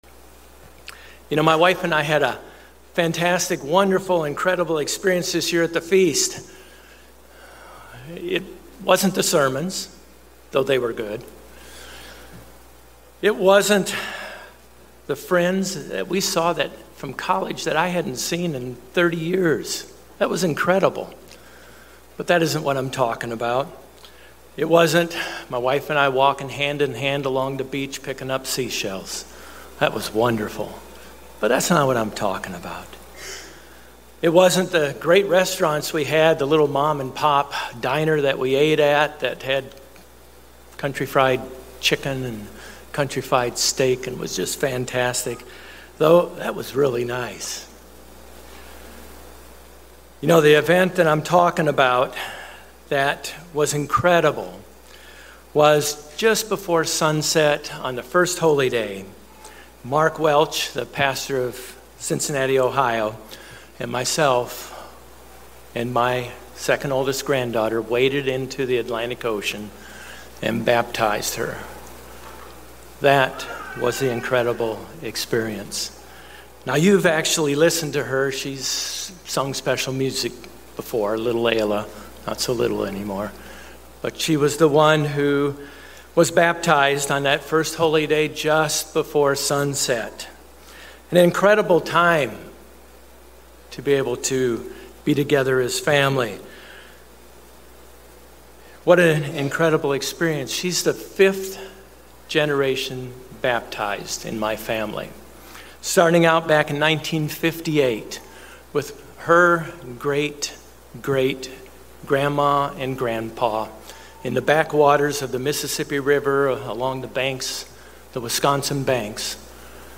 God has set up the ceremony of Baptism as a symbol of the death, burial and resurrection of our old self to a new creation. In this sermon we look at the process that leads up to baptism, what baptism is, who should be baptized, how should you be baptize and other related topics.
Given in Orlando, FL